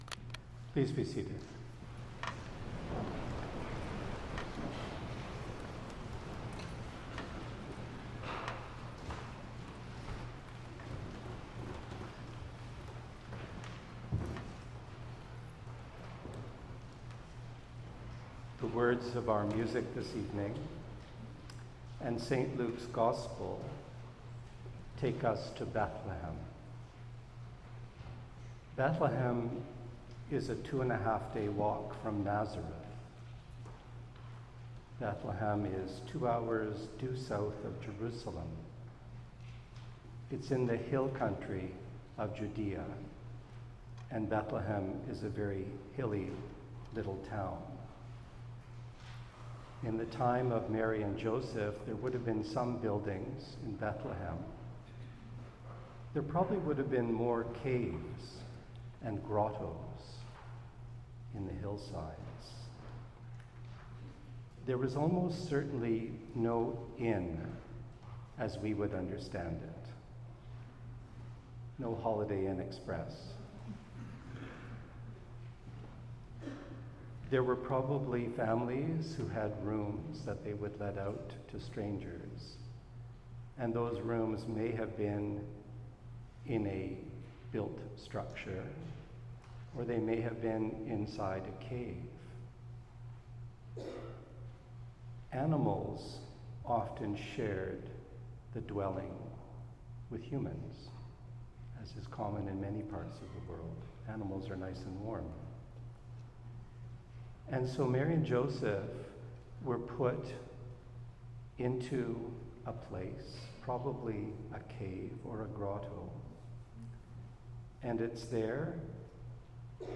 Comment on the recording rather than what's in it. Christmas Eve 2024 at 10 pm